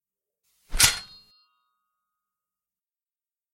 中世纪的战斗 " 锯齿剑
描述：用Rode SE3录制 从厨房刀具，金属片和复制品剑以及蔬菜中分层金属刮擦声，以获得血腥冲击力。
Tag: 动作 切片 战斗 武器